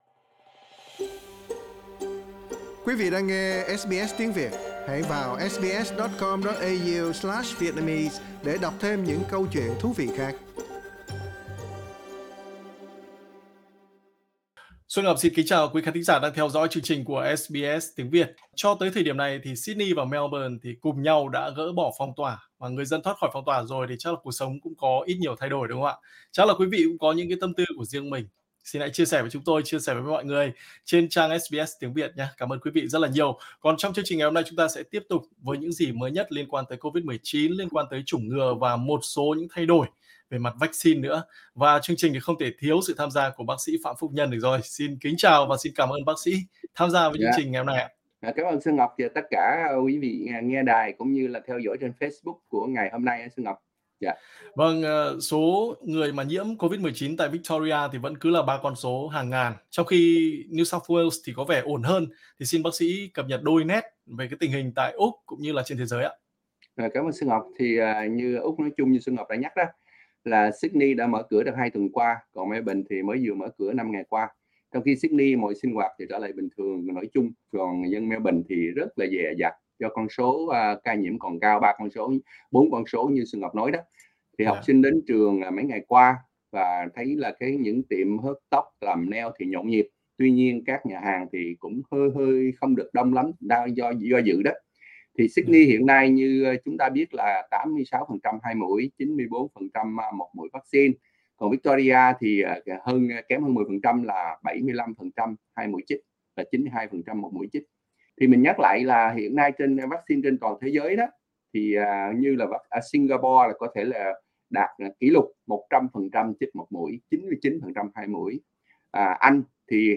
Mời quý vị nghe đầy đủ nội dung chính trong cuộc phỏng vấn ở phần âm thanh bên trên: Tình hình vắc-xin tại Úc và thế giới Giải thích lý do Melbourne chích vắc-xin đạt gần 80% đủ hai mũi mà số ca melbourne vẫn cao và tiếp tục mở cửa có nguy hiểm không?